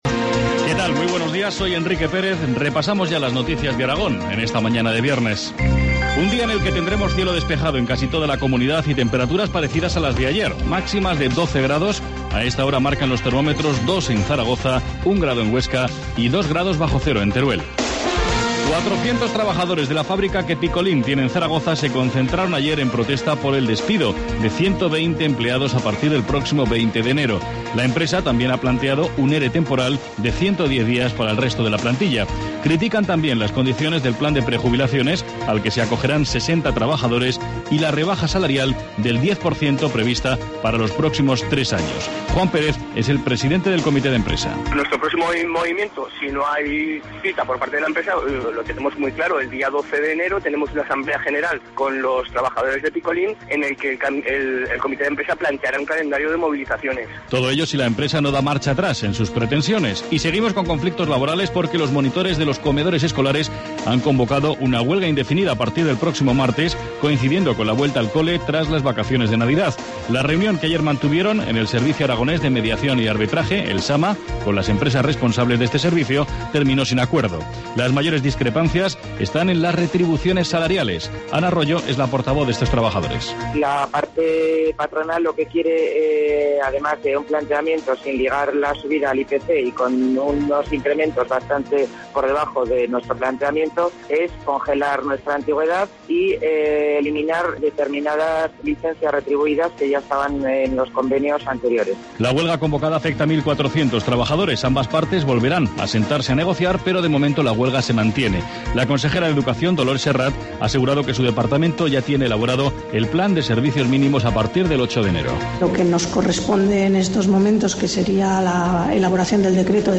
Informativo matinal, viernes 4 enero, 8,25 horas